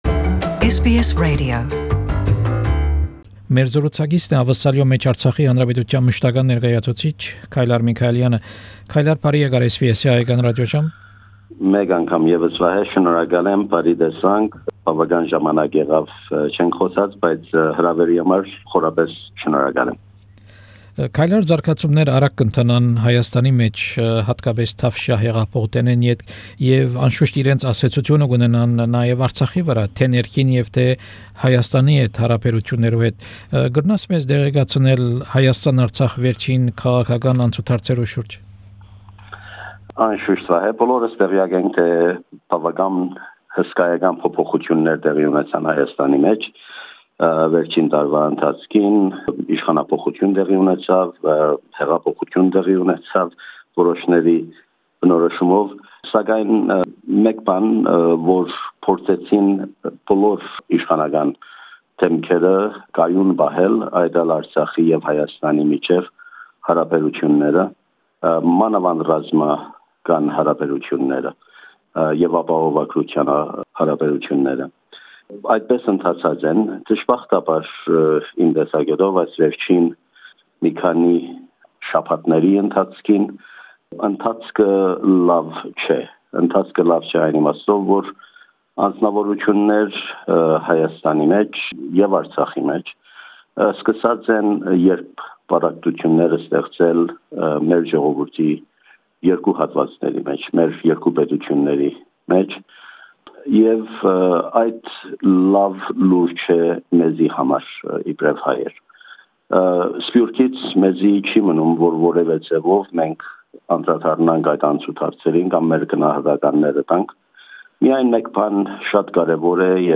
An interview (in Armenian) with Mr. Kaylar Michaelian the permanent representative of Artsakh Republic in Australia. Topics of the interview include: relations between Artsakh and Armenia, Australian federal elections and Artsakh, opening ceremony of pan-Armenian games in Artsakh, ARI project and the 2019 CONIFA (Confederation of Independent Football Associations) games in Artsakh.